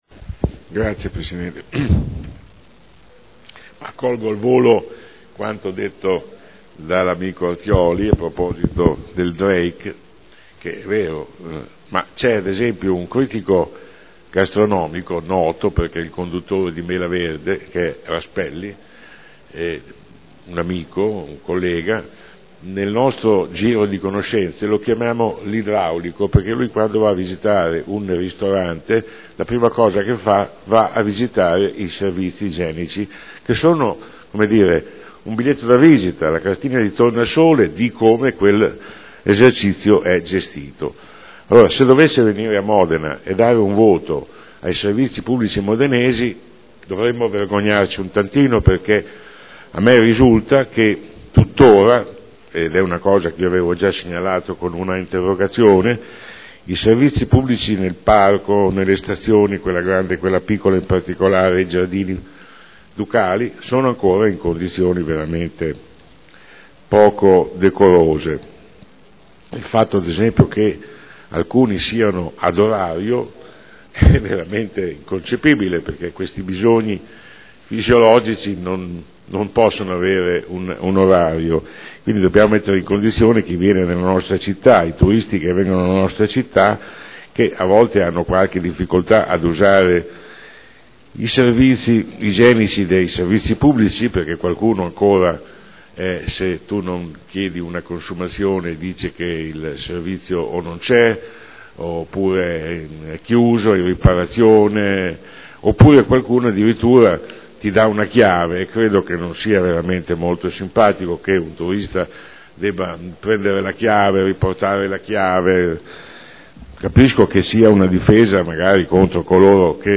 Seduta del 12/11/2012 Dibattito su Delibera.